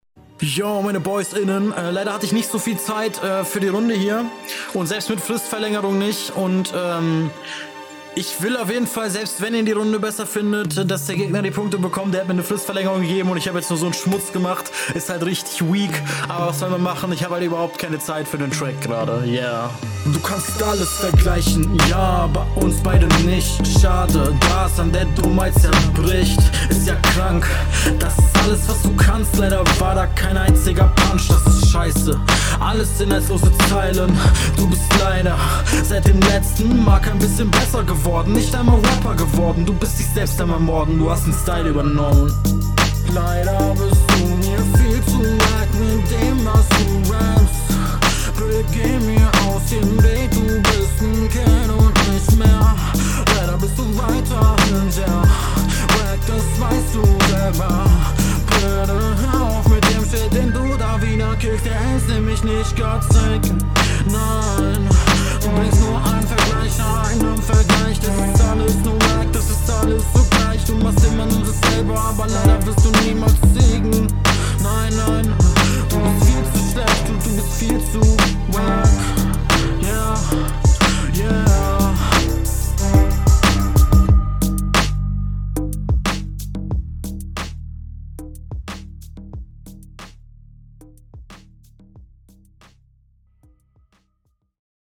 Nö.. du bekommst den Punkt denn du hast auf den KollegahBeat besser gerappt und hattest …